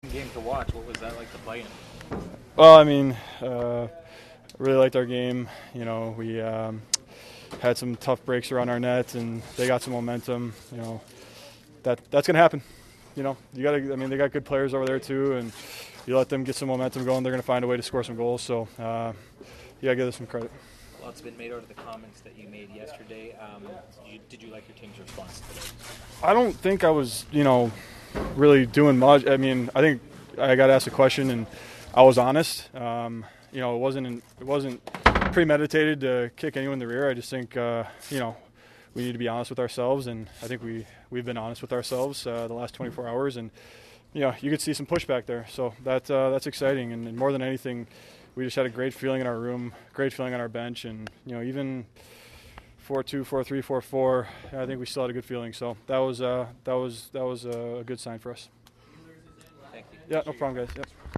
Post-game audio from the Jets dressing room.